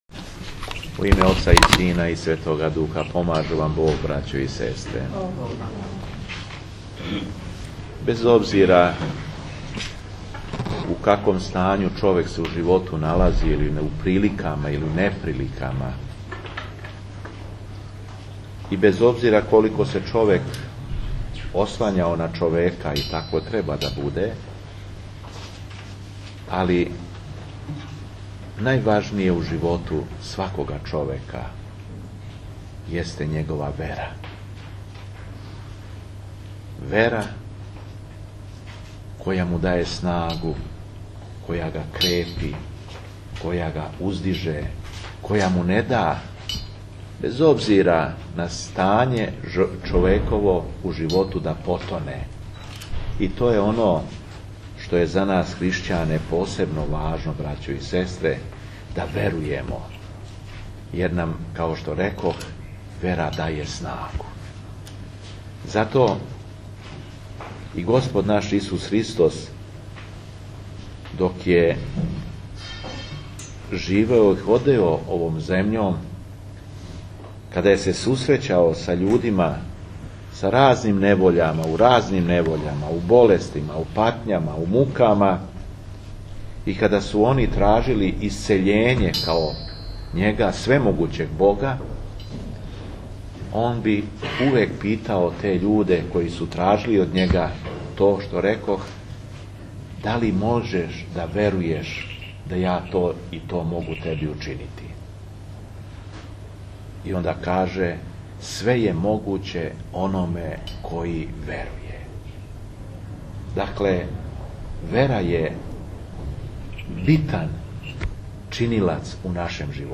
Беседа епископа шумадијског Г. Јована на Туциндан 05. јануара 2010. године